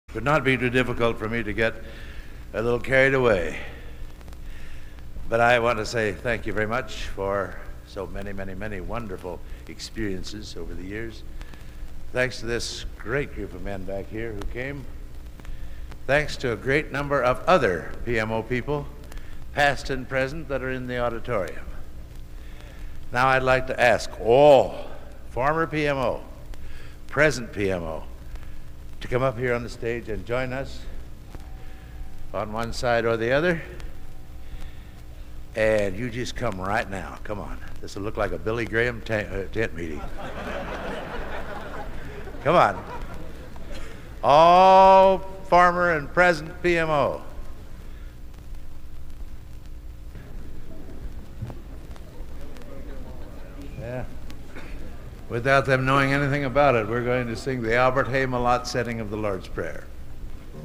Location: West Lafayette, Indiana
Genre: | Type: Director intros, emceeing